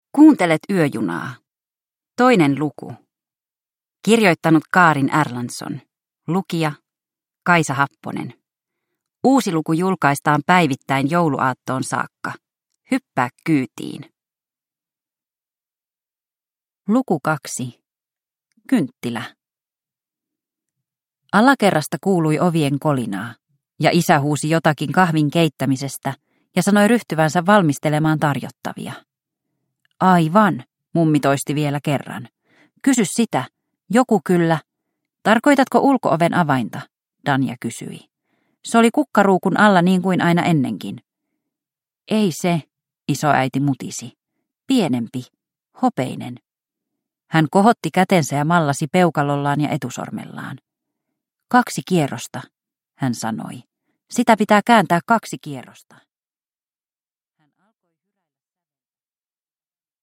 Yöjuna luku 2 – Ljudbok